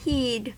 Ääntäminen
IPA: /bə'ʔaχtn̩/